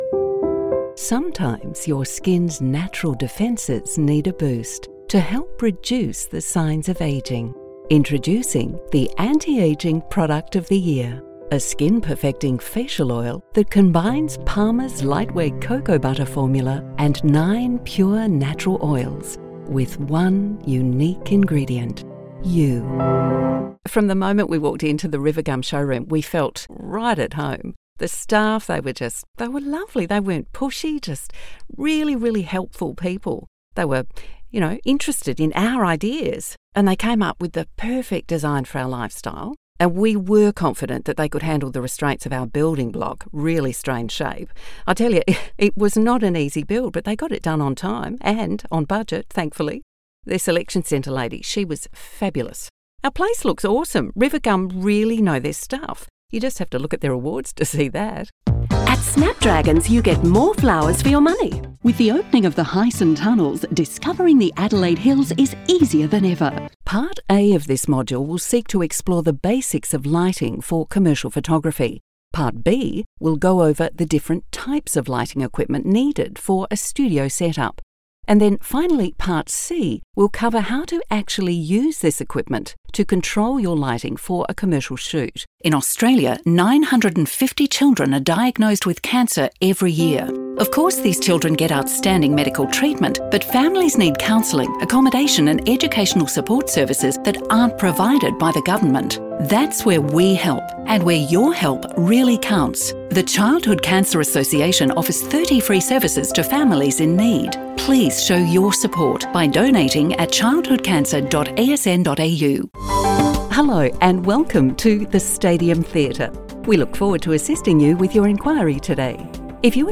HOFFMAN GROUP (Germany) Voice Over Commercial Actor + Voice Over Jobs
My voice style runs the gamut from "regular guy" to "empathetic" to "voice of authority". I speak in both a standard "Midwestern" accent and a more "Southwestern twang"....
Pro voice actor w/ a pro-equipped, broadcast quality, home studio.
My range is generally low to mid-range, but I can be VERY versatile.